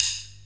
D2 SDRIM06-L.wav